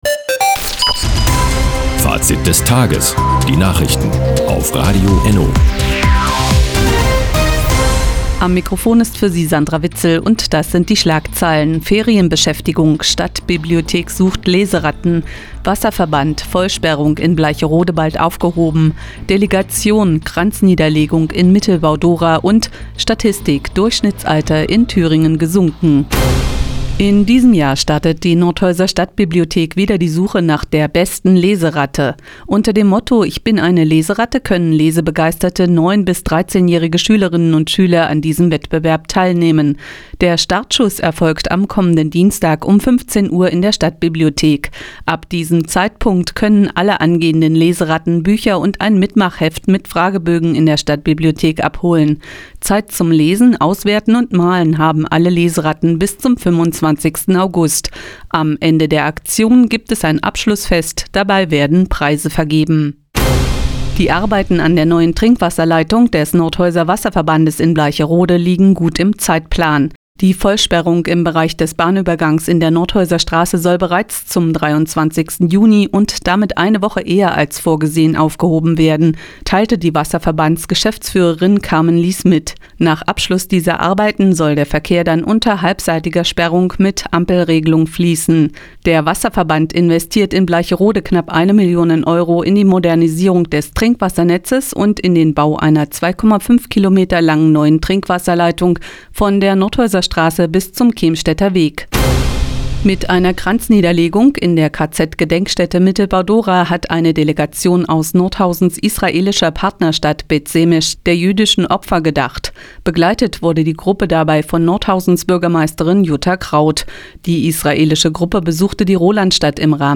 Di, 16:53 Uhr 13.06.2017 Neues von Radio ENNO Fazit des Tages ENNO (Foto: ENNO) Seit Jahren kooperieren die Nordthüringer Online-Zeitungen und das Nordhäuser Bürgerradio ENNO. Die tägliche Nachrichtensendung ist jetzt hier zu hören...